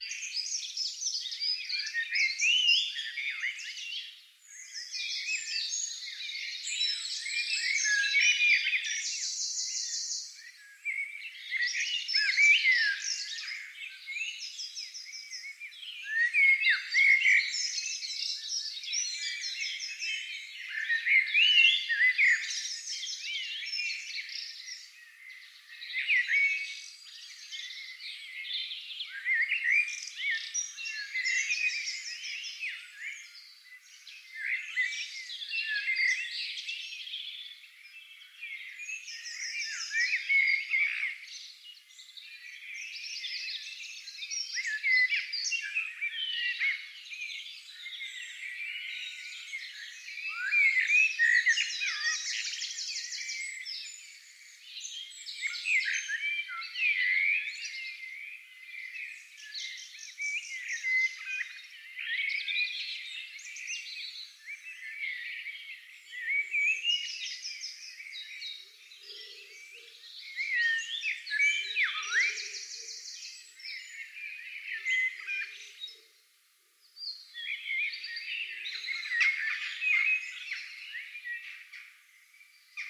Звуки рассвета
На этой странице собраны звуки рассвета — нежные трели птиц, шелест листвы и другие утренние мотивы.
Звучание чудесного весеннего рассвета с пением птиц